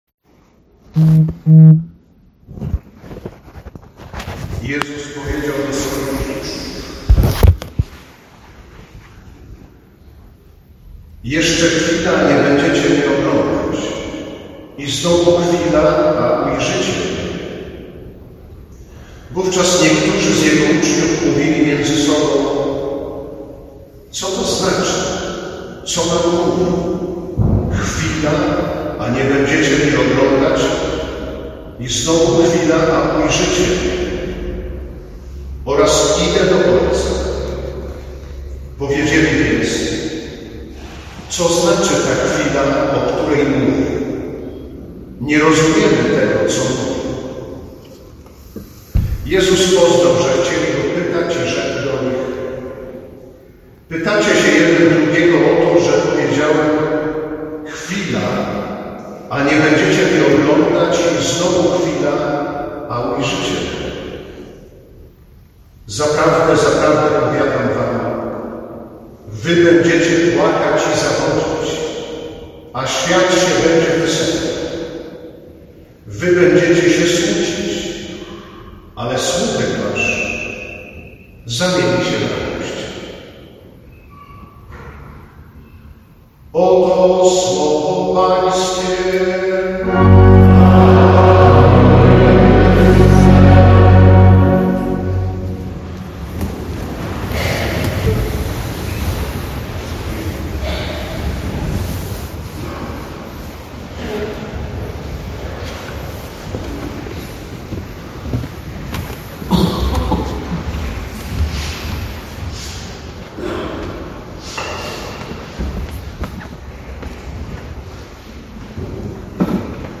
(w późniejszym czasie będzie dostępna lepsza wersja nagrania audio)